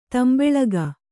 ♪ tambeḷaga